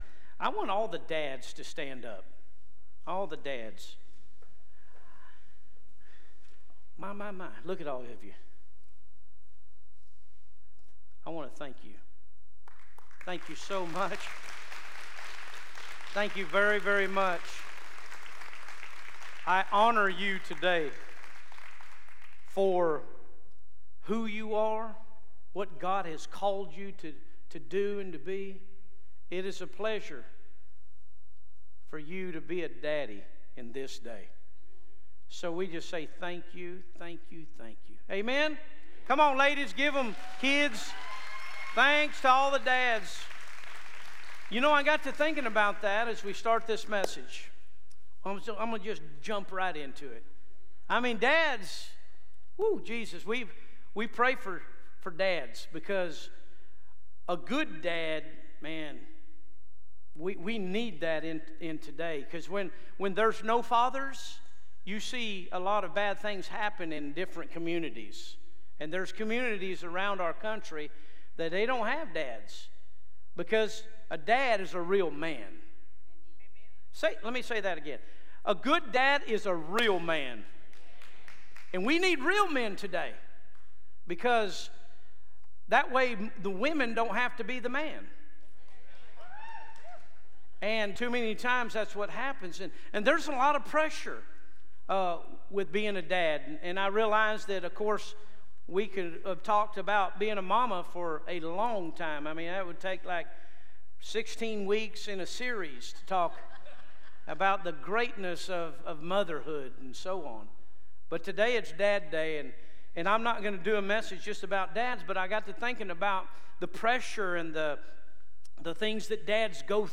Live Stream Our Complete Service Sunday mornings at 10:30am Subscribe to our YouTube Channel to watch live stream or past Sermons Listen to audio versions of Sunday Sermons